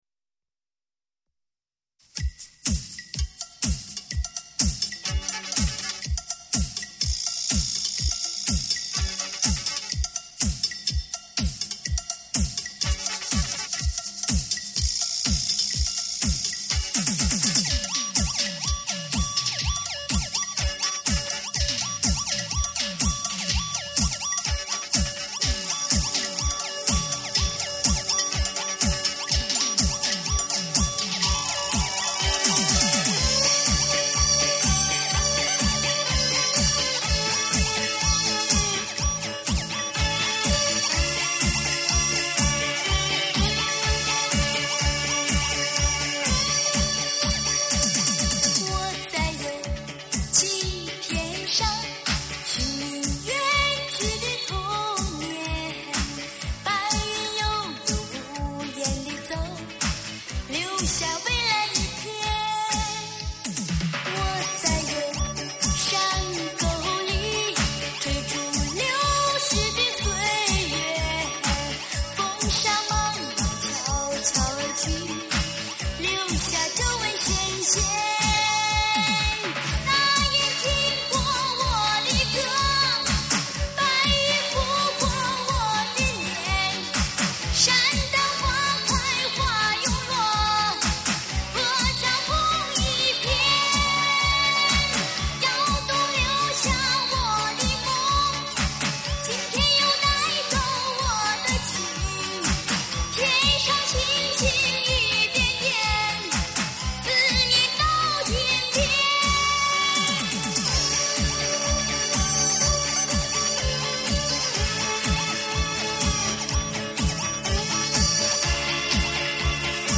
喜欢的老歌，西北风。
摇滚黄土情韵，领衔95风云。